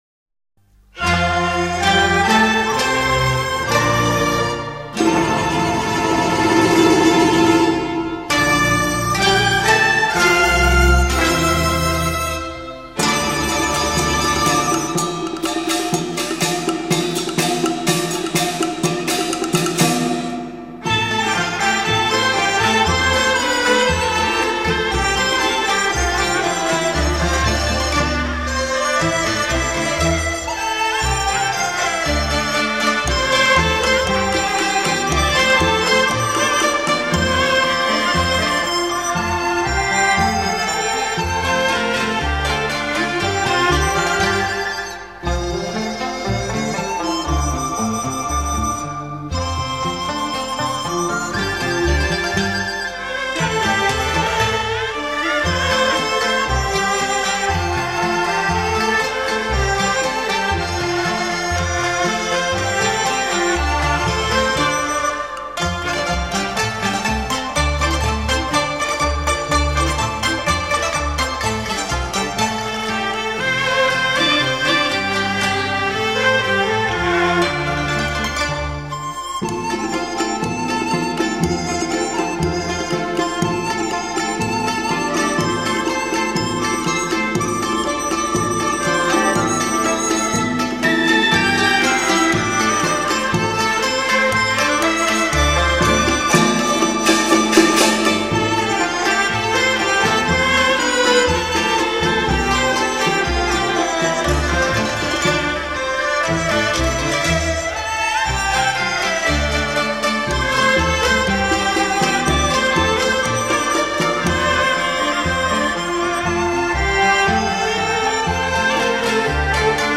古朴优美、典雅庄重，不但具有极美的艺术欣赏价值，也是研究宋、